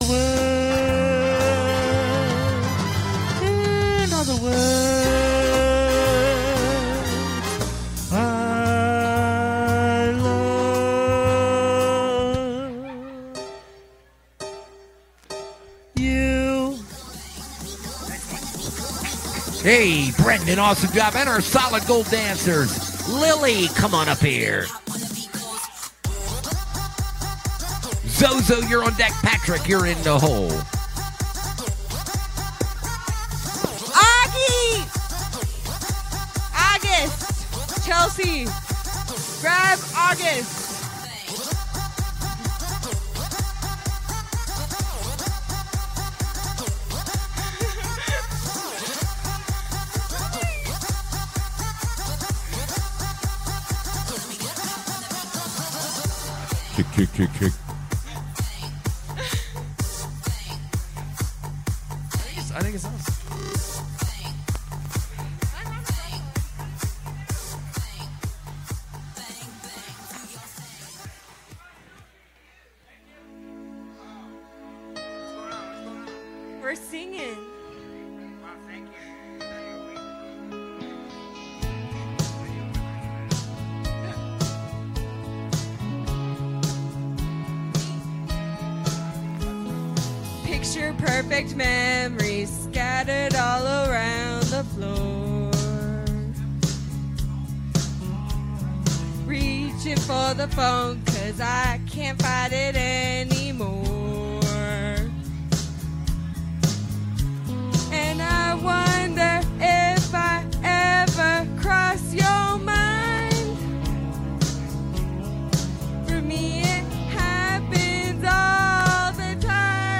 We are live 8-12 every Sunday and Wednesday from the Morrison Holiday Bar